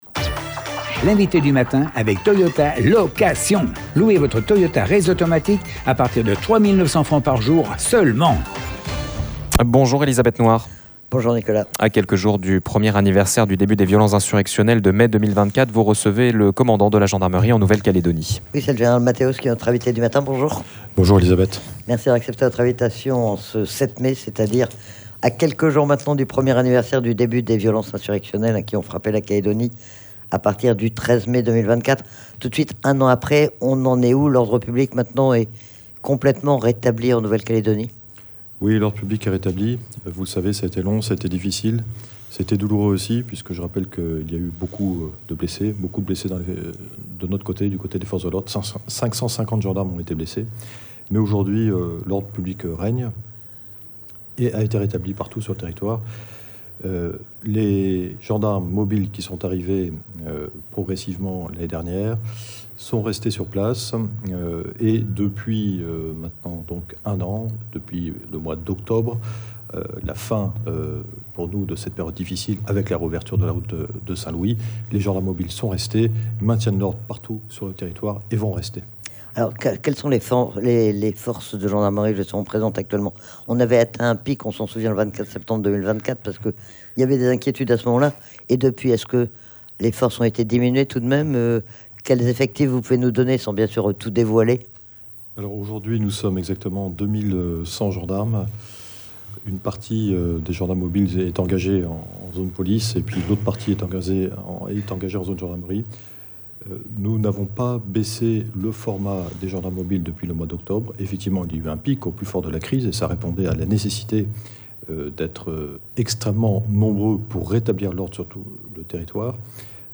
C'est le général Matthéos, commandant la gendarmerie en Nouvelle-Calédonie qui est, tout à l'heure, notre invité du matin. À quelques jours de l'anniversaire du début des violences insurrectionnelles du 13 mai 2024, nous l'avons interrogé sur la situation sur le terrain et sur le dispositif de sécurité qui est mis en place.